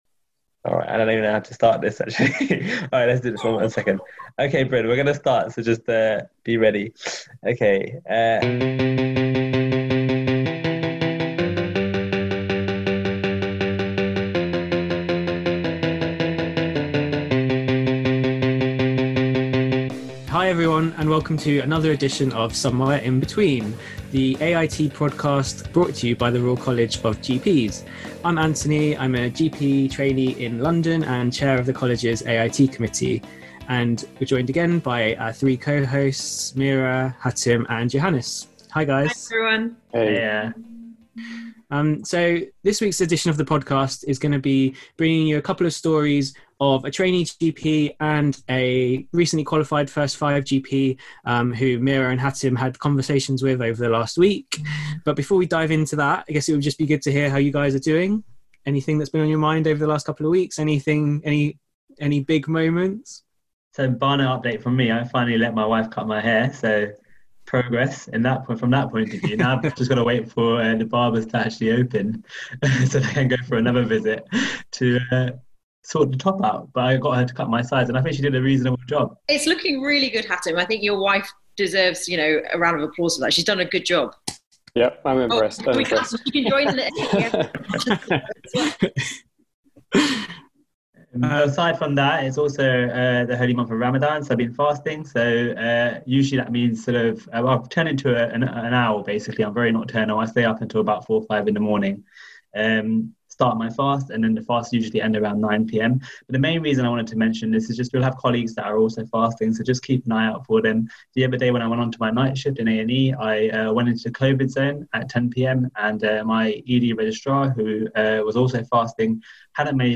The One with Our First Regional Accent